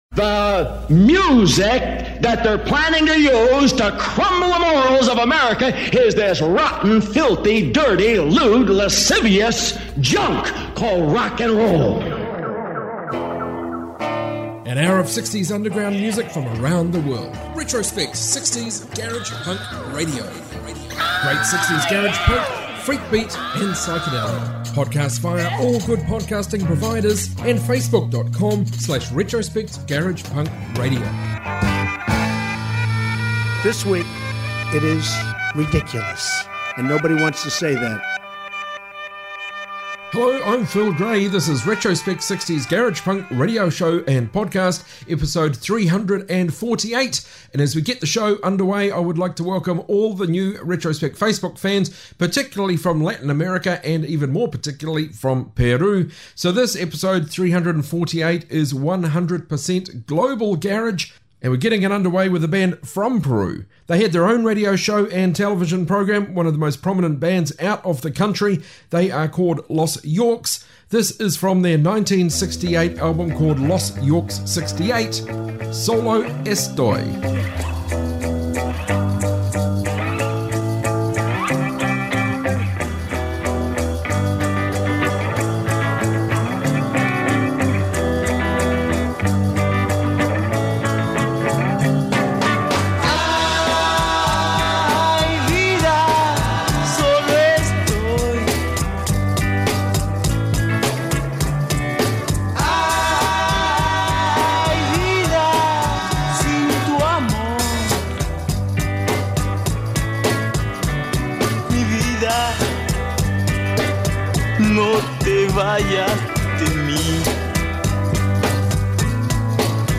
60s global garage